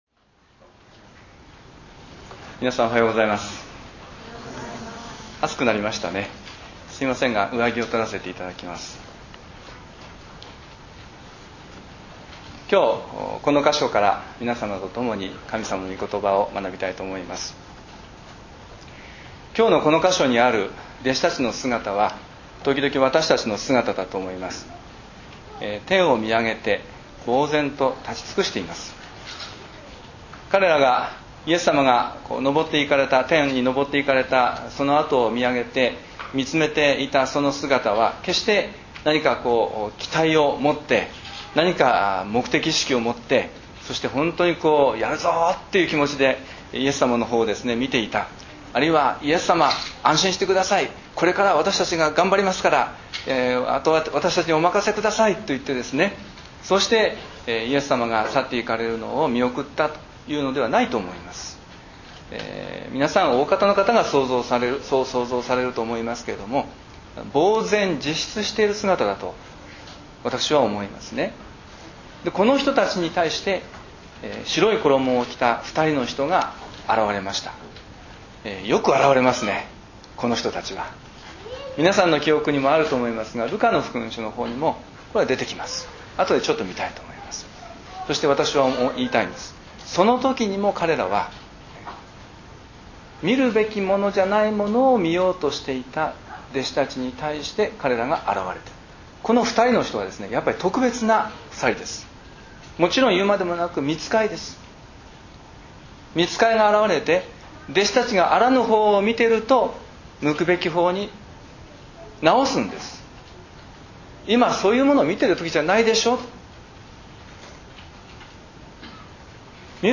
礼拝宣教録音 － 目を地に、そして地の果てへ